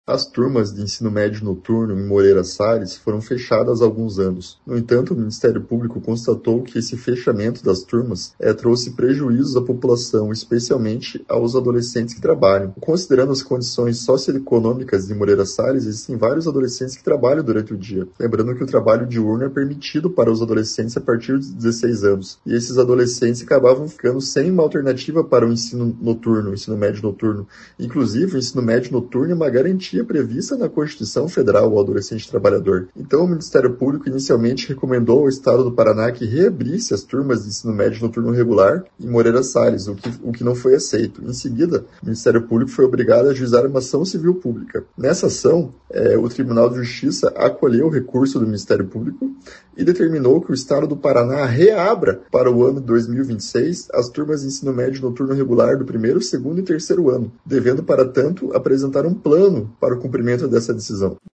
Ouça o que diz o promotor de Justiça Rogério Rudinik Neto: